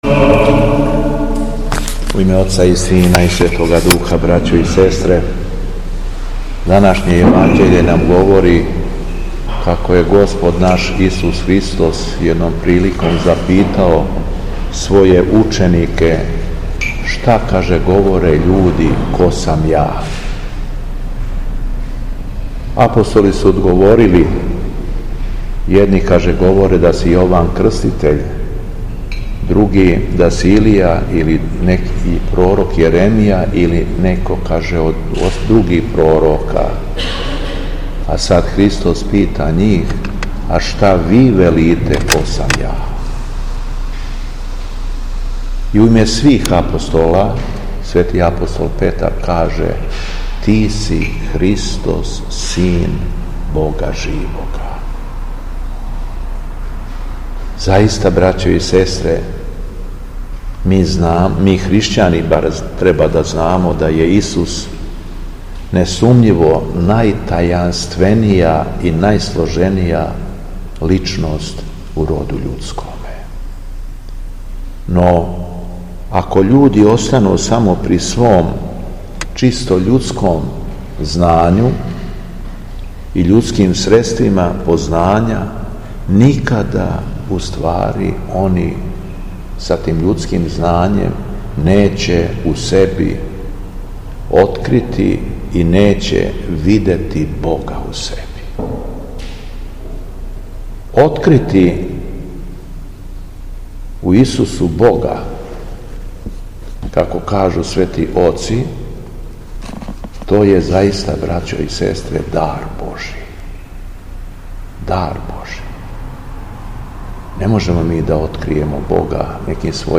У петак, 26. септембра 2025. године, када се наша Света Црква молитвено сећа освећења храма Христовог Васкрсења као и Свештеномученика Корнилија сотника, Његово Високопреосвештенство Митрополит шумадијски г. Јован служио је Свету Архијерејску Литургију у храму Свете Петке у крагујевачком насељу Вино...
Беседа Његовог Високопреосвештенства Митрополита шумадијског г. Јована
После прочитаног јеванђелског зачала Високопреосвећени Митрополит се обратио беседом сабраном народу рекавши: